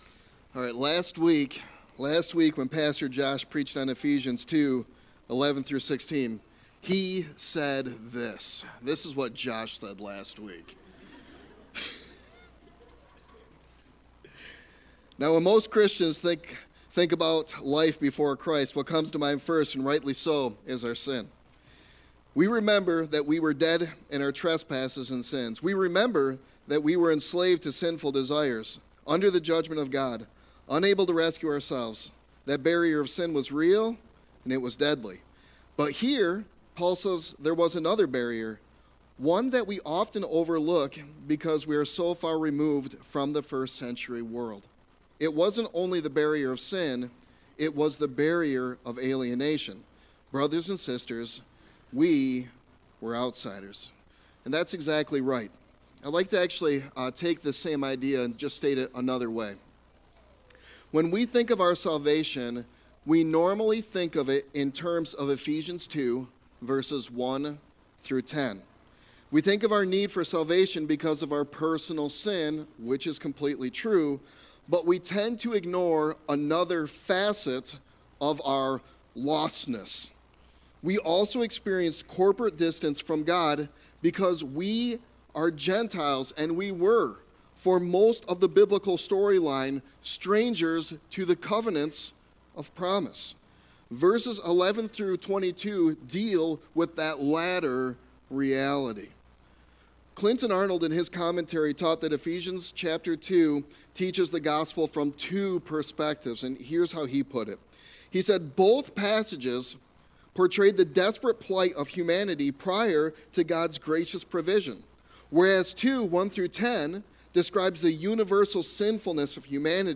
Sermon Text: Ephesians 2:17-22